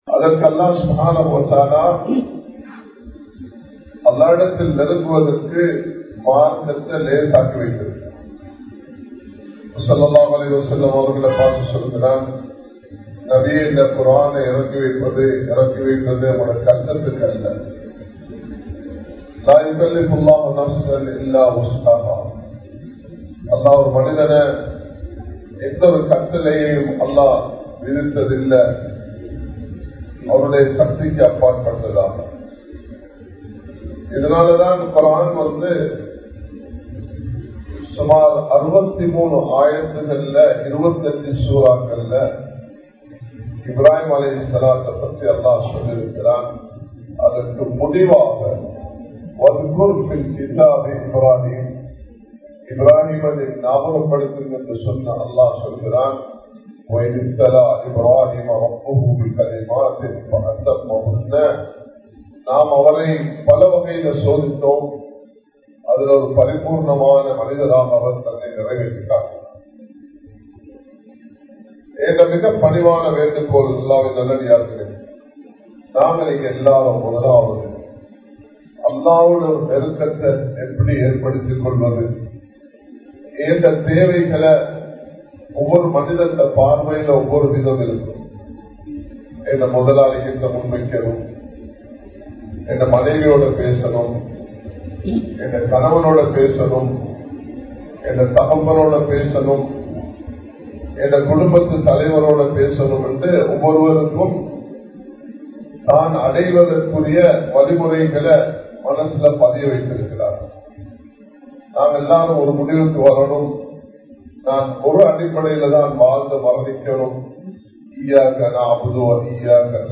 ஈமான் & அறிவின் சிறப்புகள் | Audio Bayans | All Ceylon Muslim Youth Community | Addalaichenai